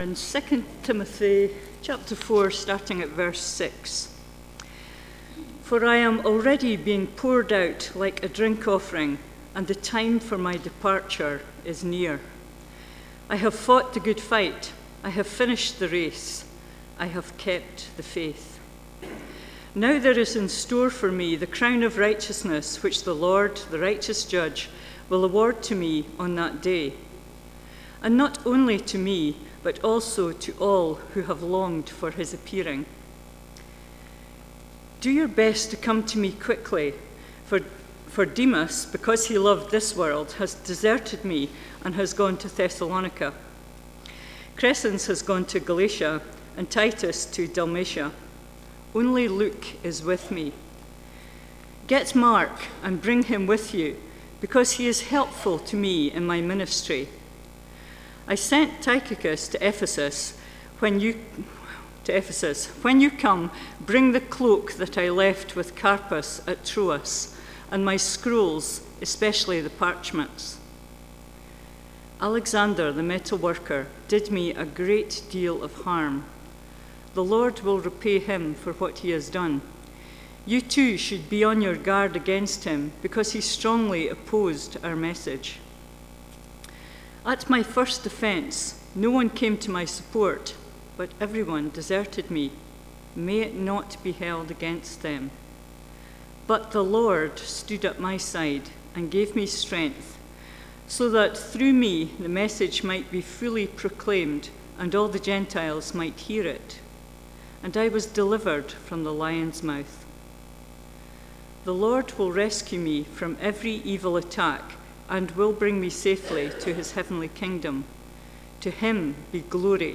2 Timothy Passage: 2 Timothy 4:6-22 Service Type: Sunday Morning « “Preach the word” The birth of John the Baptist foretold »